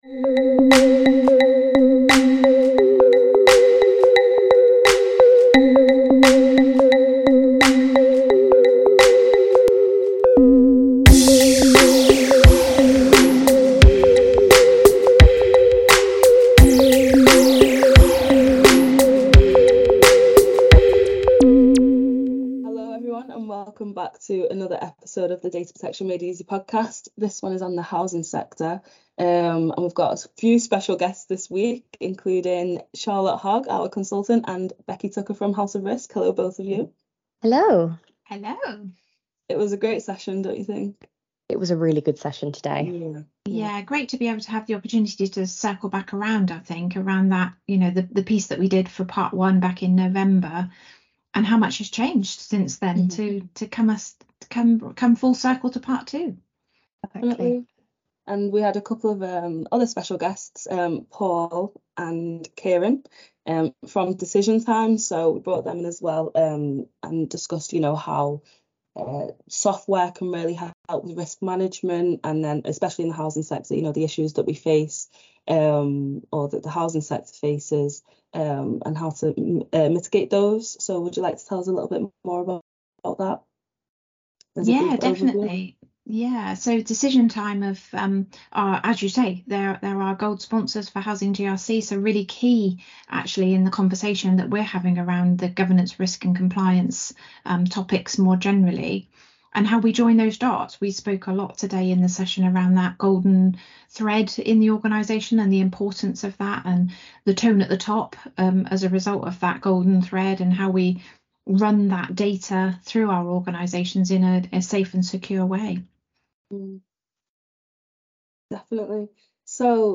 is joined by 4 special guests from the housing sector to discuss the housing reform bill and its potential impact on data protection.